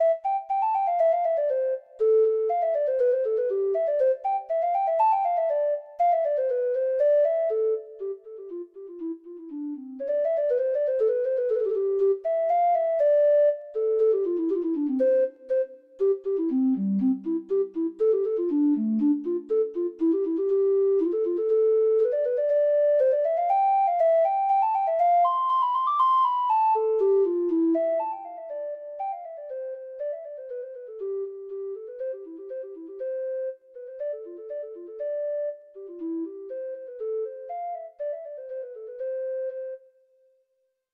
Traditional Trad. Planxty Lady Blaney (Irish Folk Song) (Ireland) Treble Clef Instrument version
Irish